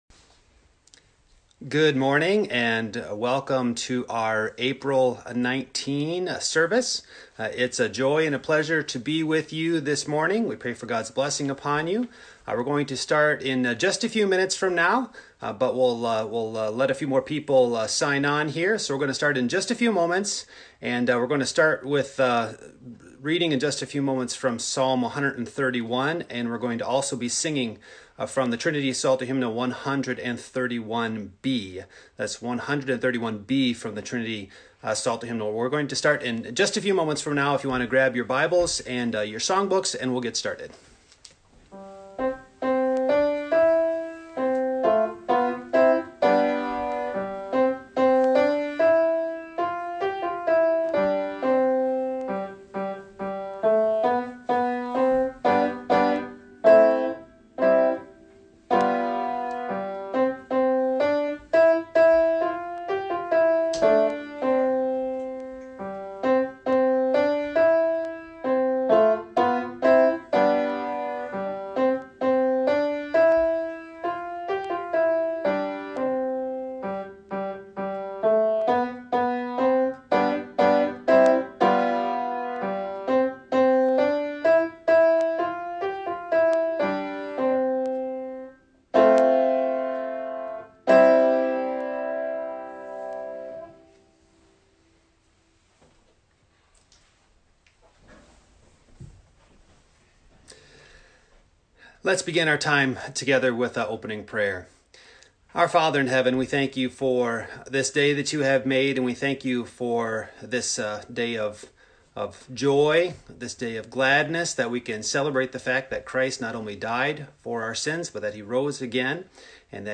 Passage: Luke 20:45-47 Service Type: Morning